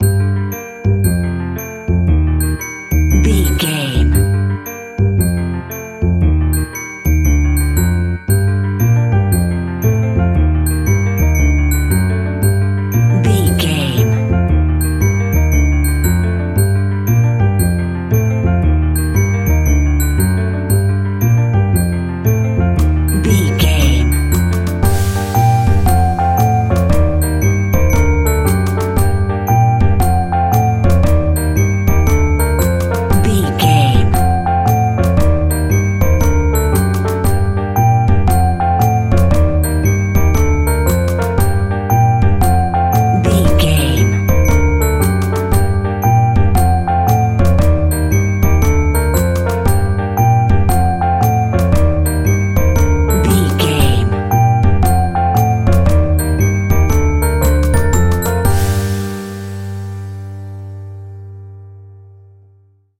Aeolian/Minor
scary
ominous
dark
haunting
eerie
double bass
electric organ
piano
drums
electric piano
instrumentals
horror music